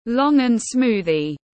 Sinh tố nhãn tiếng anh gọi là longan smoothie, phiên âm tiếng anh đọc là /lɔɳgənˈsmuː.ði/